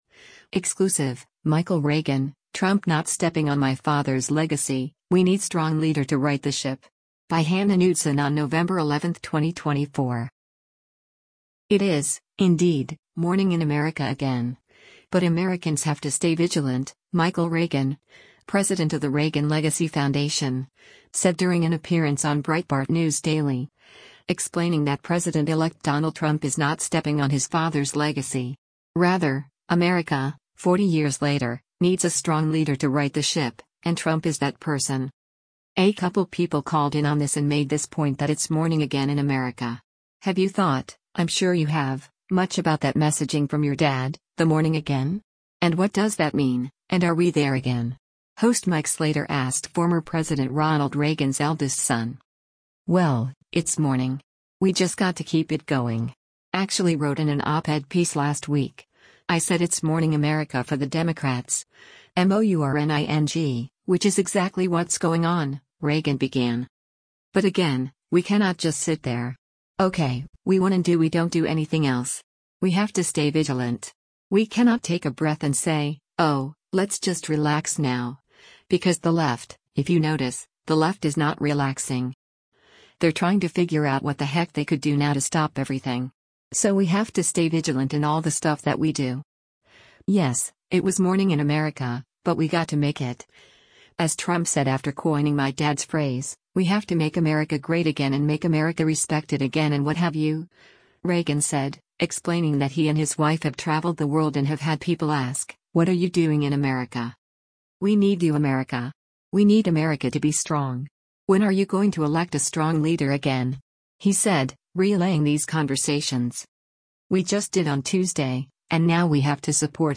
It is, indeed, morning in America again, but Americans “have to stay vigilant,” Michael Reagan, president of the Reagan Legacy Foundation, said during an appearance on Breitbart News Daily, explaining that President-elect Donald Trump is not stepping on his father’s legacy.